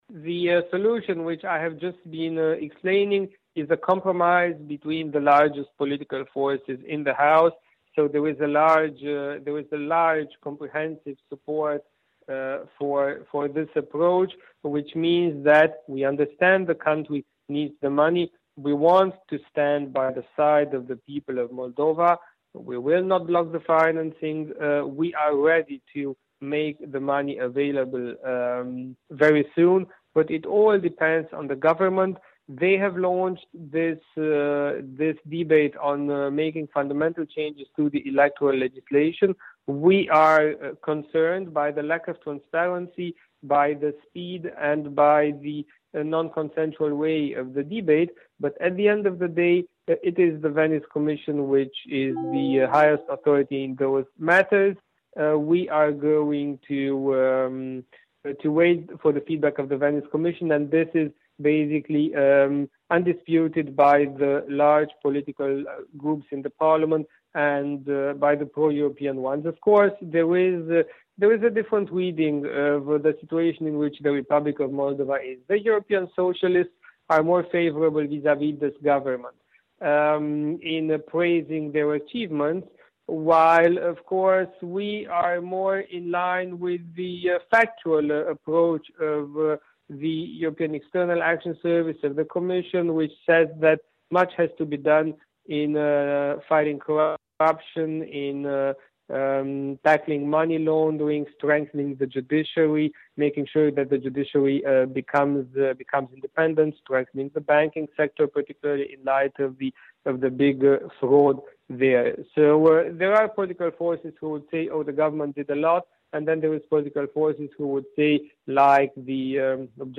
Un interviu cu europarlamentarul român din fracțiunea PPE.